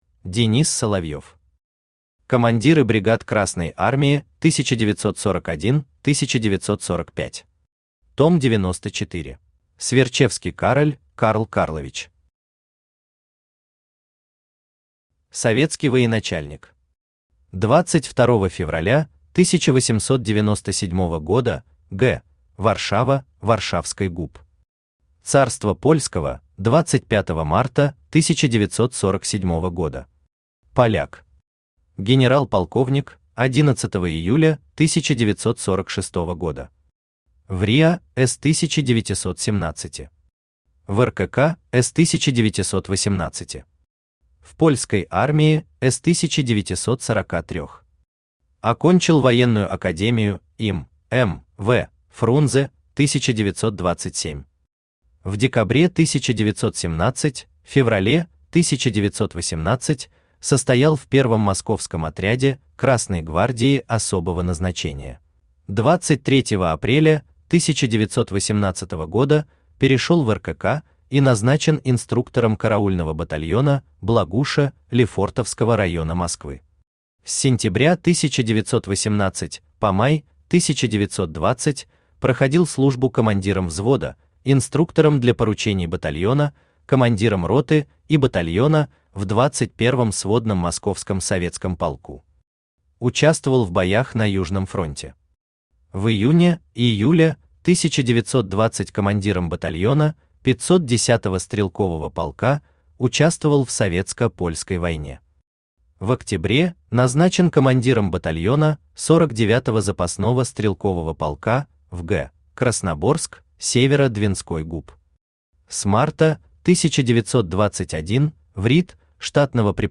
Аудиокнига Командиры бригад Красной Армии 1941-1945. Том 94 | Библиотека аудиокниг
Aудиокнига Командиры бригад Красной Армии 1941-1945. Том 94 Автор Денис Соловьев Читает аудиокнигу Авточтец ЛитРес.